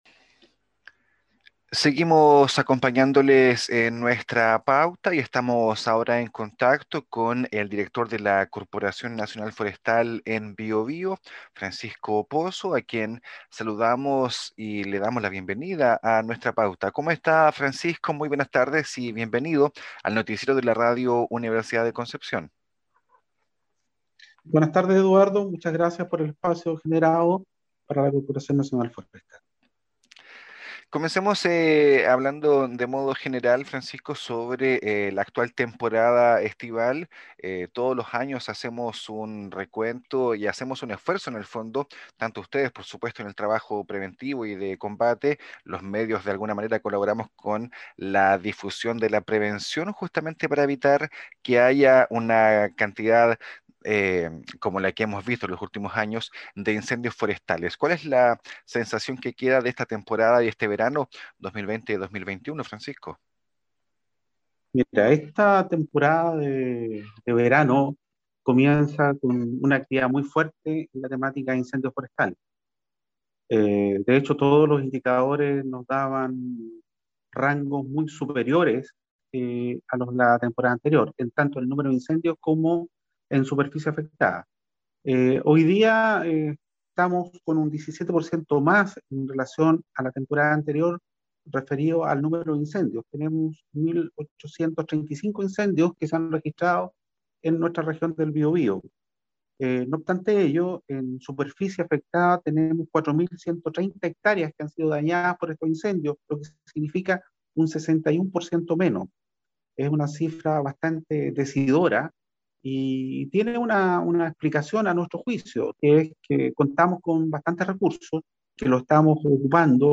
En entrevista con Nuestra Pauta, el director de Conaf señaló que las condiciones climáticas anticipaban un mayor número de eventos y, al mismo tiempo, una mayor superficie comprometida.
Entrevista-Conaf-Biobio-11-febrero.mp3